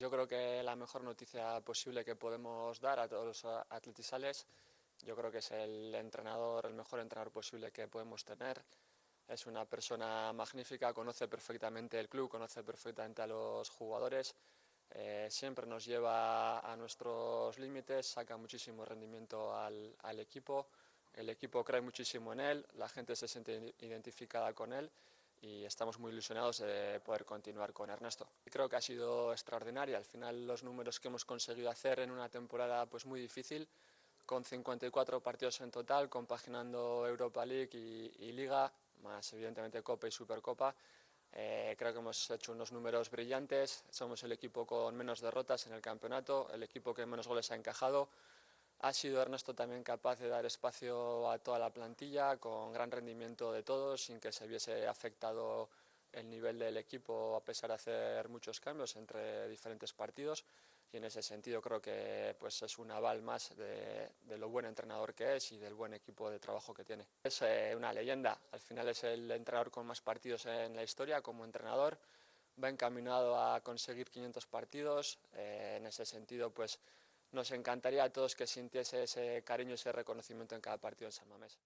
Javier Clemente, en la entrevista concedida a La Movida Deportiva de Onda Vasca, ha querido también incidir y valorar los nuevos parámetros del fútbol que están extendidos y predominan en las canteras de los principales clubs del mundo.